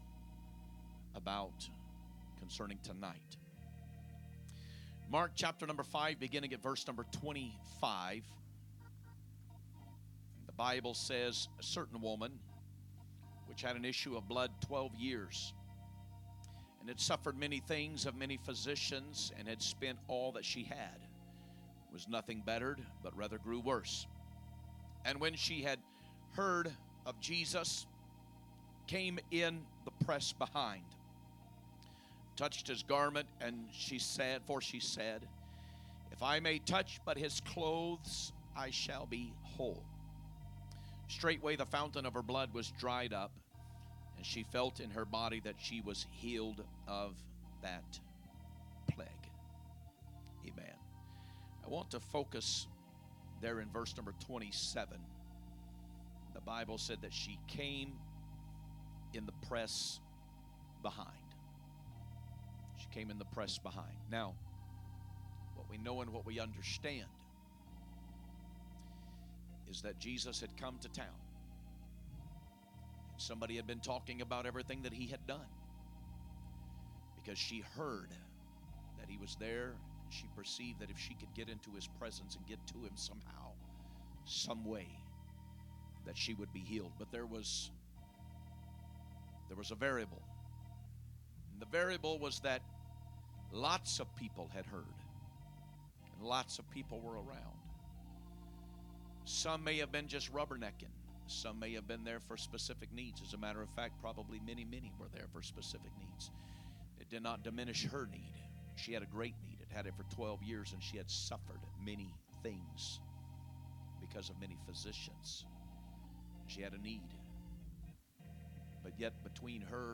From Series: "2025 Preaching"
11/2/2025 Sunday Evening Service - Lesson 7 - Pressing Through Opposition